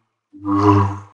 clash.wav — two sabers meeting. Fires on new agent sessions and task abort.